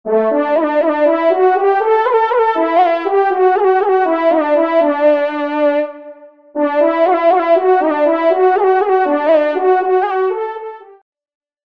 Genre :  Fanfare de circonstances
Pupitre de Chant